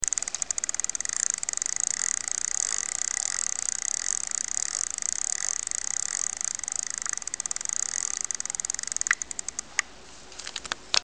Ho provato a far ruotare l'asse centrale a motore spento prima di montarlo e ho riscontrato i rumori che sentite nei due file audio allegati in basso
pedalata_indietro.mp3